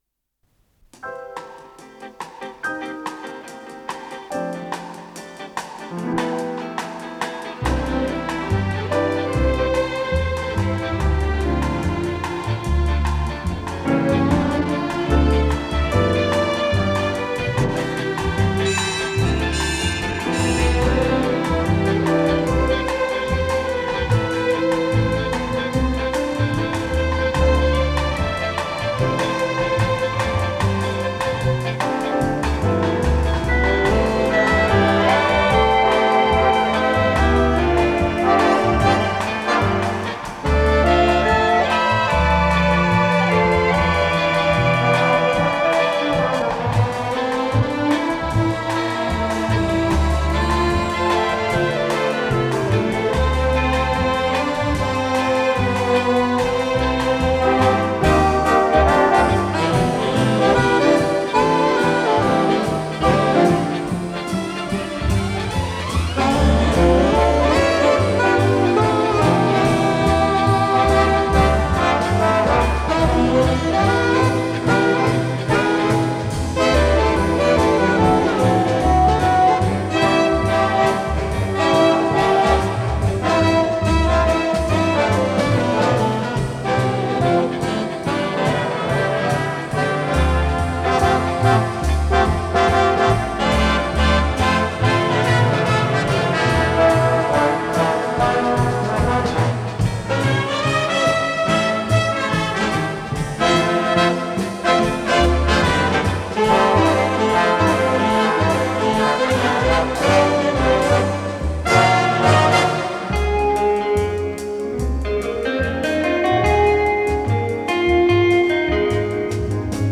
ПодзаголовокПьеса для эстрадного оркестра, ре бемоль минор
Скорость ленты38 см/с